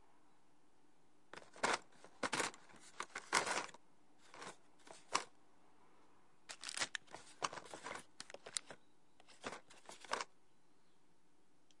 压碎爆米花
描述：破碎爆米花：用手压碎爆米花。响亮，开裂，松脆。它可以用来压碎东西或吃东西。记录在tascam DR40上
Tag: 爆米花 裂纹 开裂 破碎 粉碎 破碎 紧缩 食品 粉碎 OWI 突破 突破 捣鼓